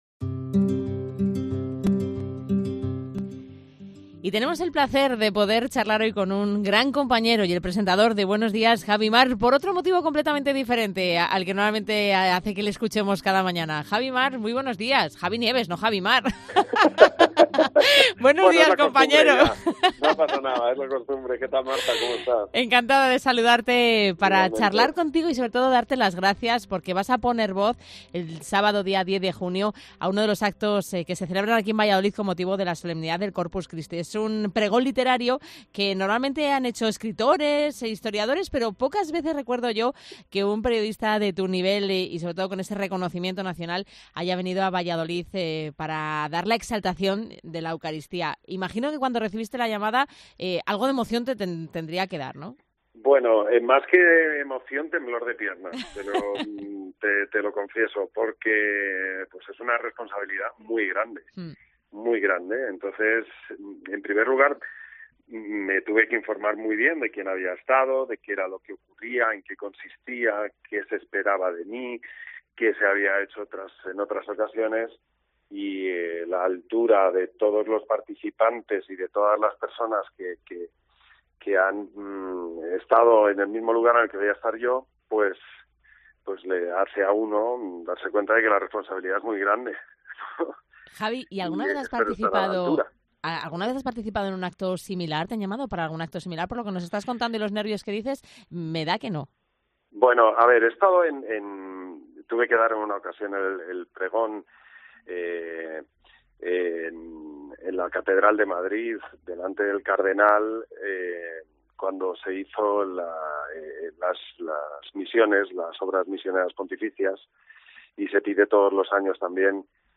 Javi Nieves, comunicador de Cadena 100: “La Fe es un pilar básico en mi vida”
“Una responsabilidad muy grande”, ha reconocido el comunicador de Cadena 100 en declaraciones a COPE Valladolid.